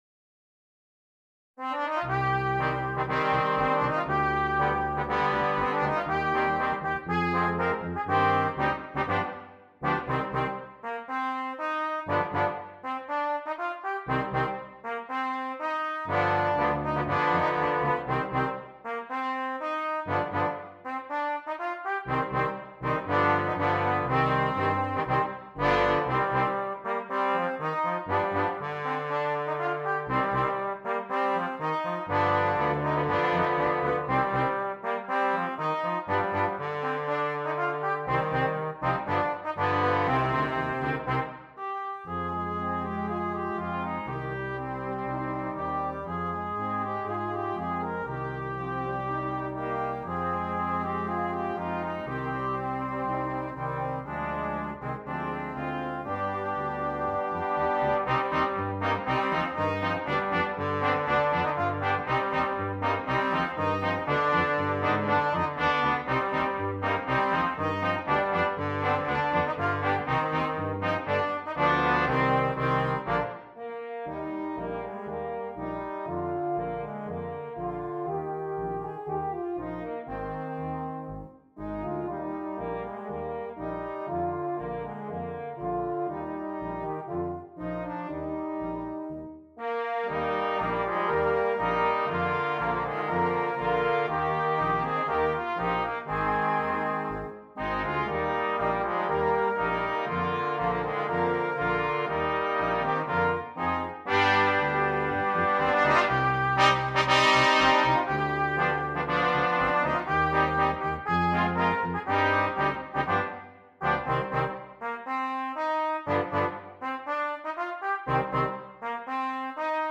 Brass Quintet
a concert march